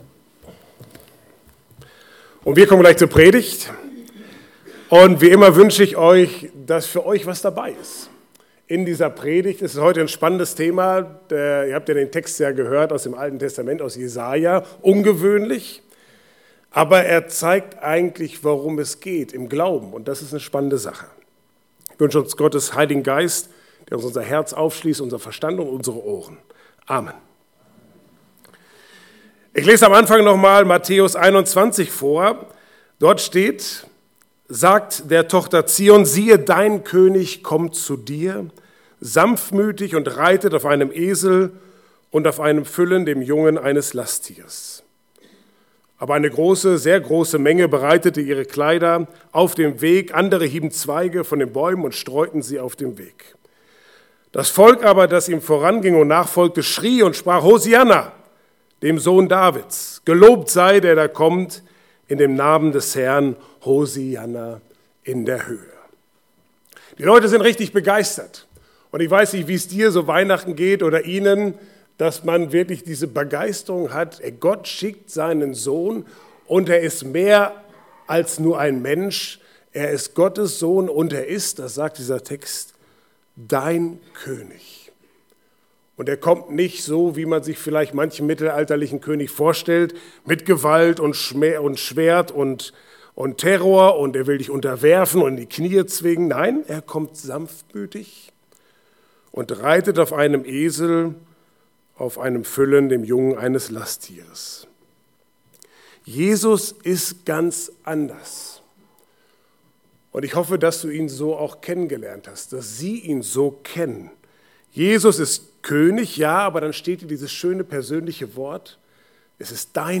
Passage: Jesaja 62, 1-5 Dienstart: Gottesdienst « Zweifel nach tiefer